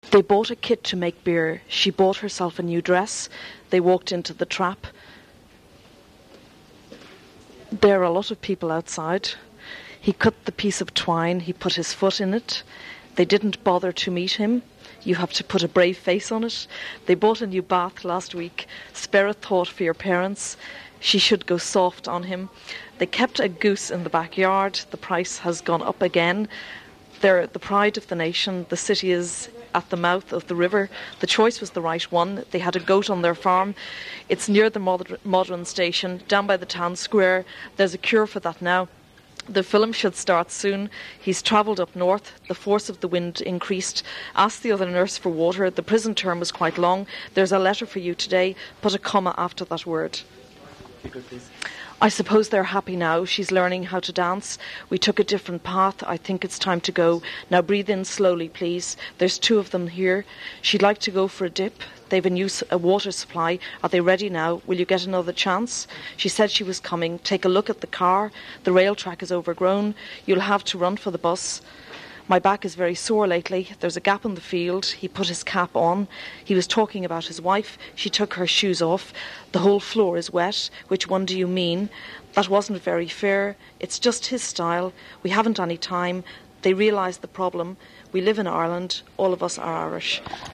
Older 'Dublin 4' accent
DUB_Finglas_F_35.wav